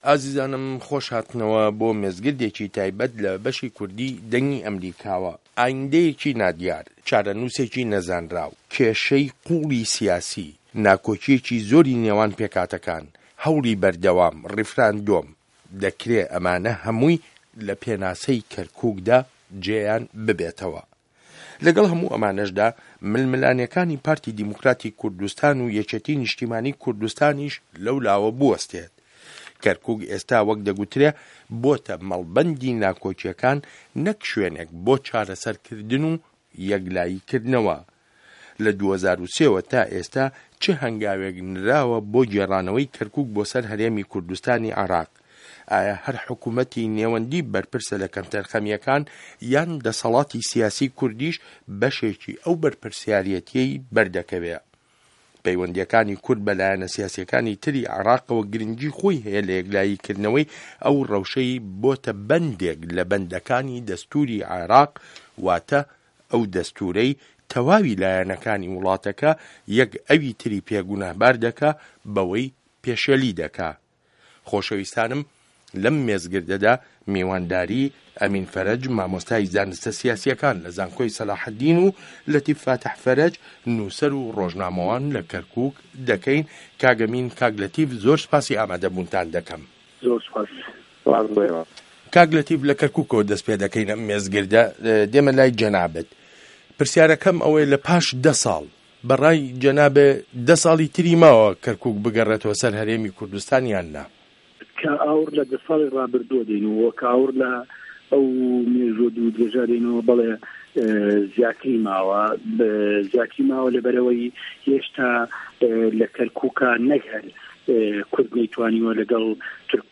مێزگرد : چاره‌نوسی که‌رکوک به‌ره‌و کوێ